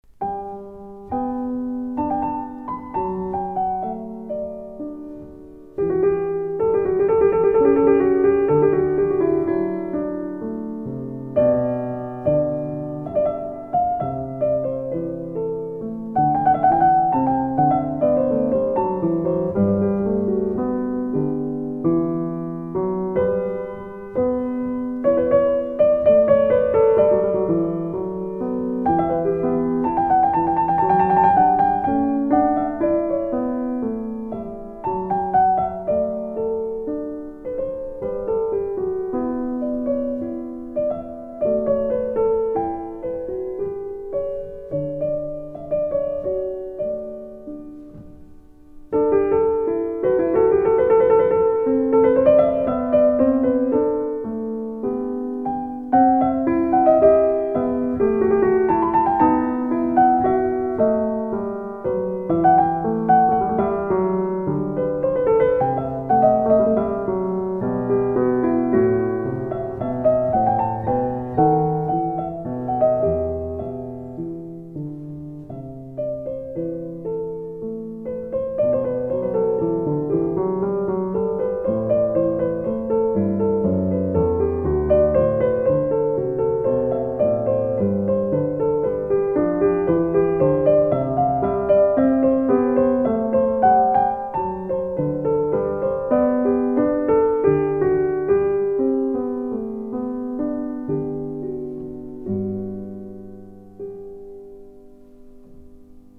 音樂類型:古典饗宴
溫暖厚實的錄音 狂飆絢麗的琴技